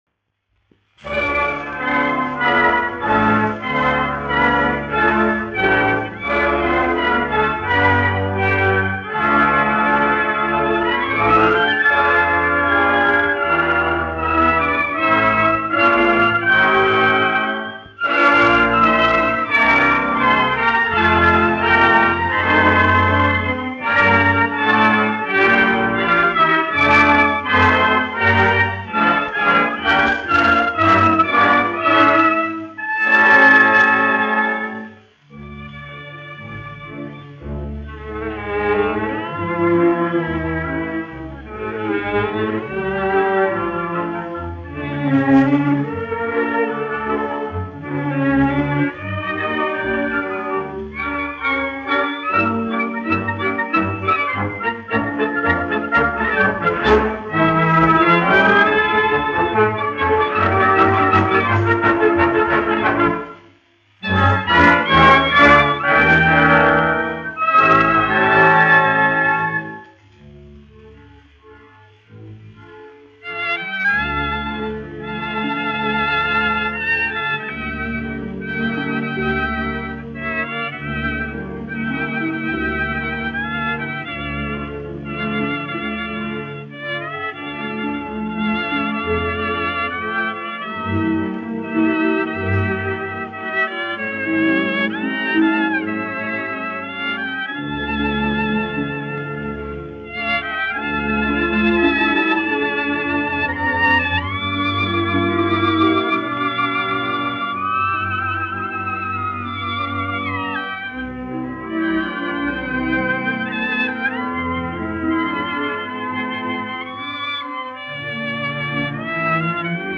1 skpl. : analogs, 78 apgr/min, mono ; 25 cm
Operetes--Fragmenti
Latvijas vēsturiskie šellaka skaņuplašu ieraksti (Kolekcija)